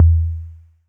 Urban Tom 02.wav